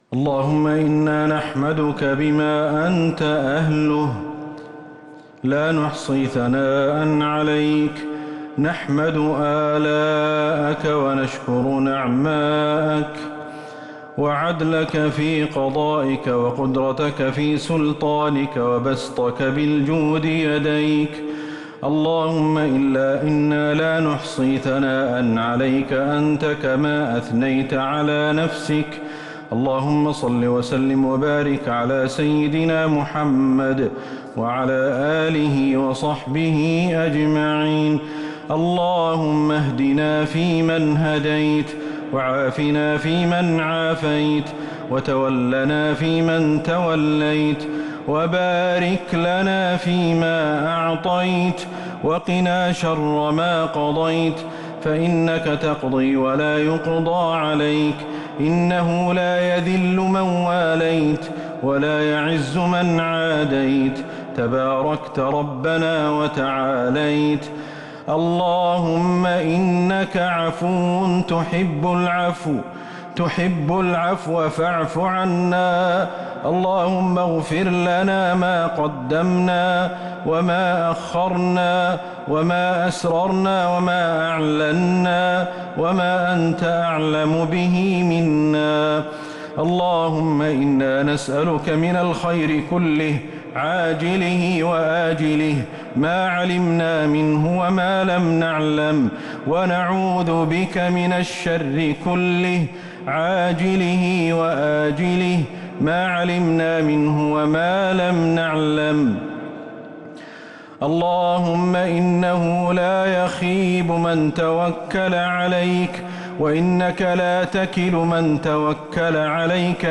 دعاء القنوت ليلة 4 رمضان 1444هـ | Dua 4st night Ramadan 1444H > تراويح الحرم النبوي عام 1444 🕌 > التراويح - تلاوات الحرمين